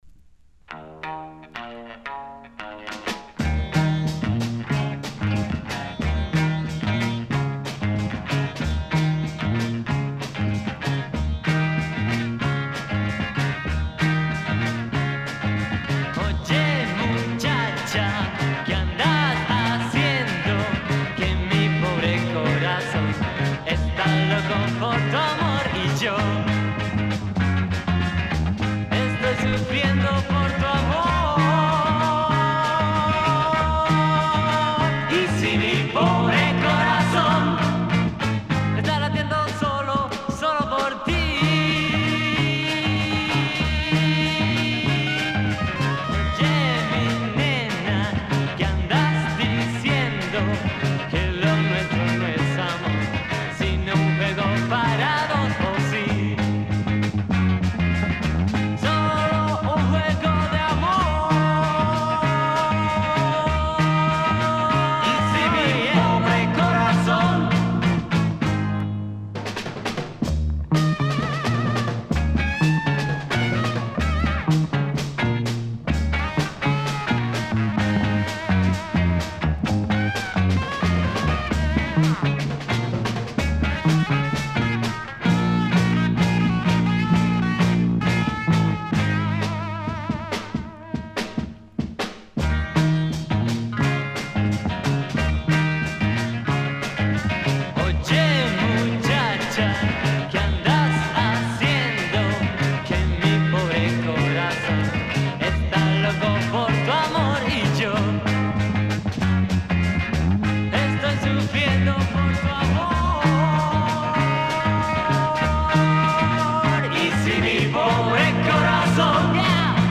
Published September 15, 2009 Garage/Rock Comments
Really like the guitar sound of these tracks.